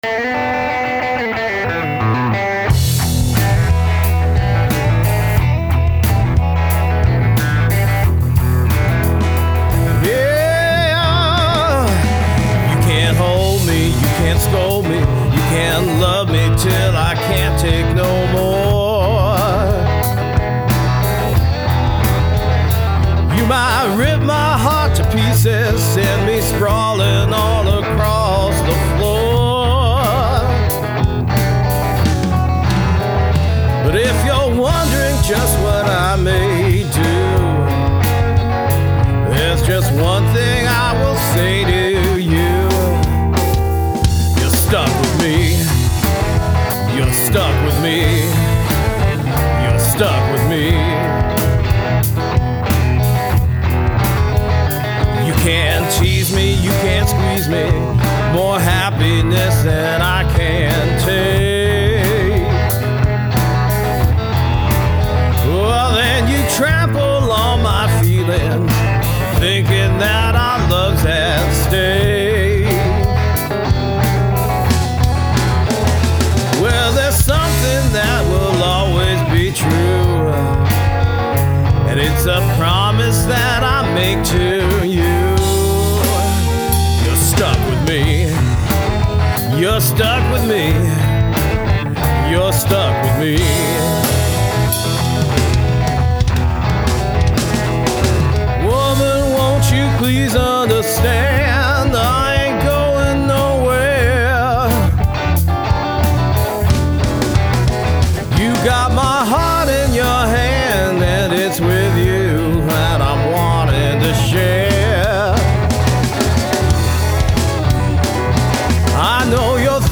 Finally, here’s a song I wrote and record awhile back (sorry for the over-abundance of bass). If I remember correctly, I used four guitars in six parts in that song (Epiphone Explorer, Gibson ES-335, MIM Strat, PRS SE Soapbar II), all recorded with the Champ – and with the stock speaker no less!